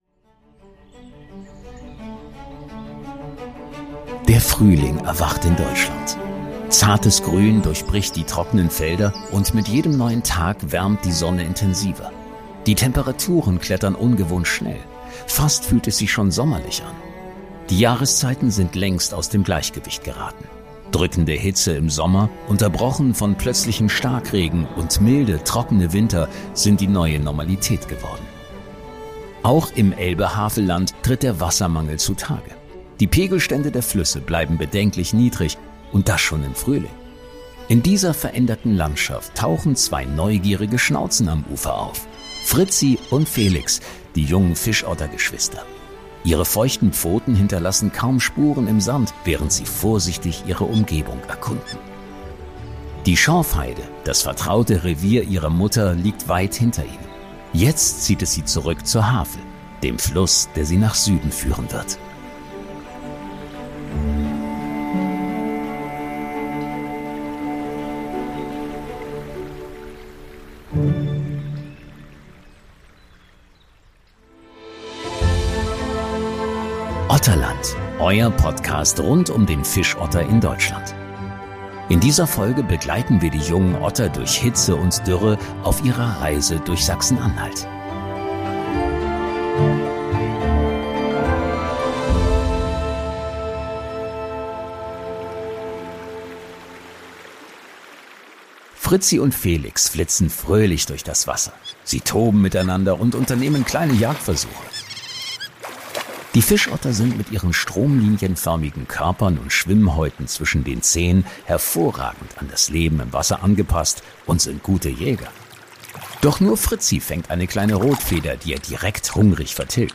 „Otterland“ ist ein Storytelling-Podcast, der eine fiktive Geschichte über eine Otterfamilie quer durch Deutschland erzählt, basierend auf realen Erfahrungen und Fakten zu Fischottern. Ein Hörabenteuer über Mut, Hoffnung, Verlust und die Suche nach einem Zuhause.